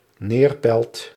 Neerpelt (Dutch pronunciation: [ˈneːrpɛlt]
Nl-Neerpelt.ogg.mp3